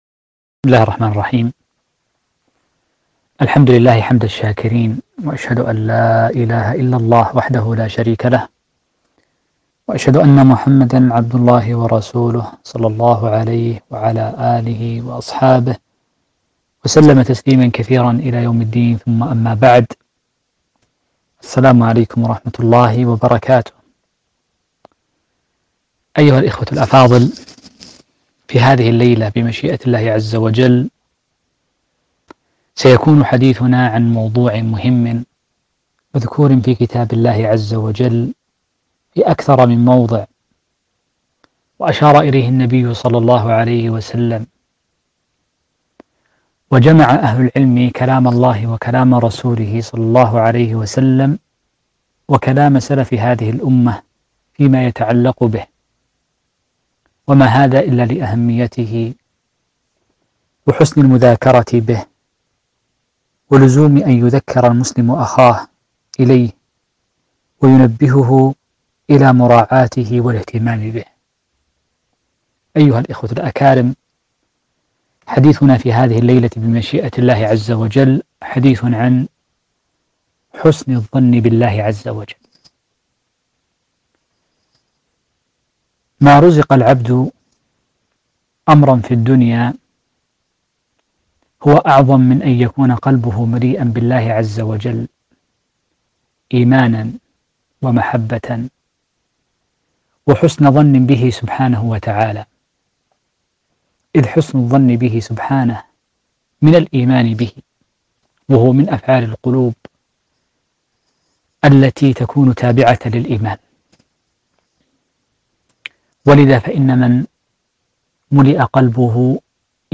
محاضرة رائعة - حسن الظن بالله عبر البث المباشر 1441 هــ